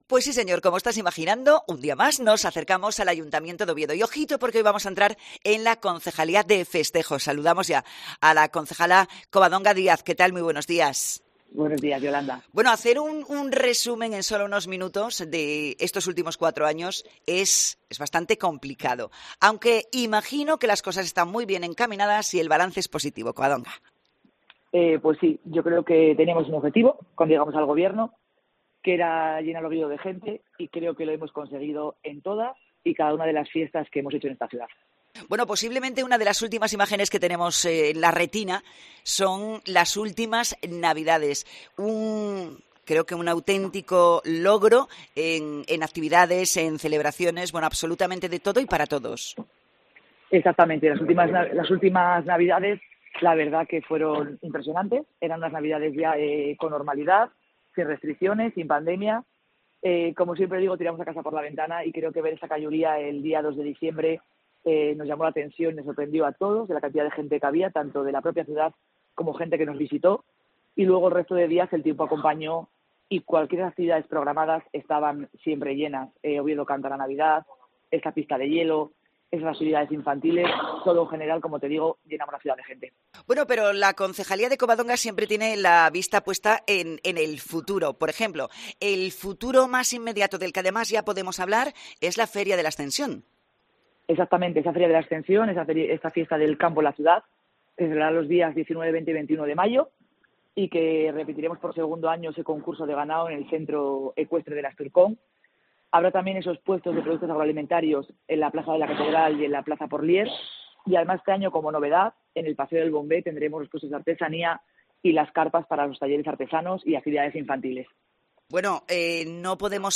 Entrevista a Covadonga Díaz, concejala de Festejos del Ayuntamiento de Oviedo